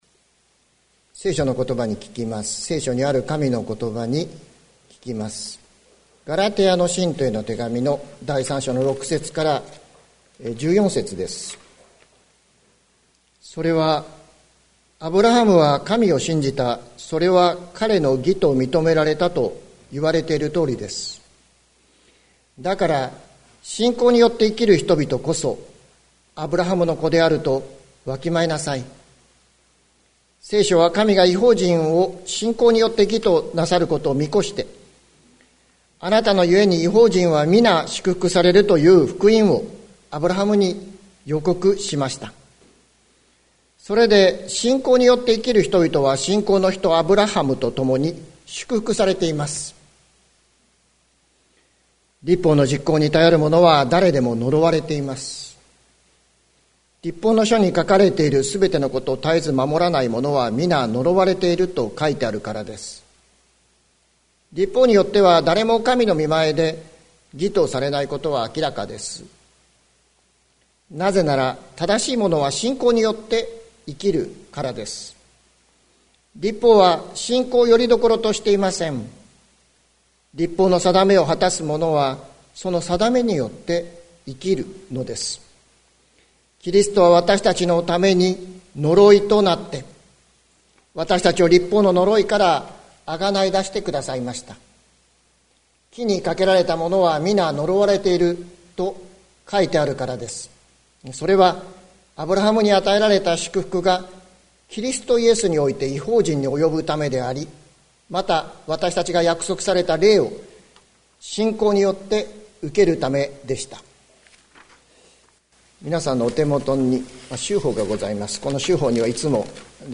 2021年05月30日朝の礼拝「呪いから祝福へ」関キリスト教会
説教アーカイブ。